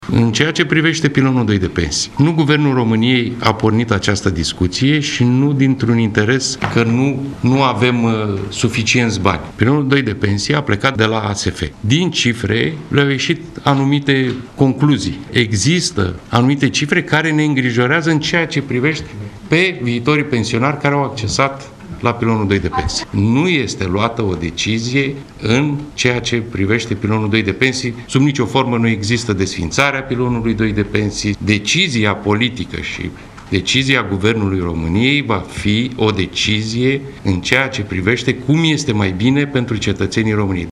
Aflat la Neptun, vicepremierul Marcel Ciolacu a spus că nu se desființează pilonul II de pensii, dar vor fi unele schimbări.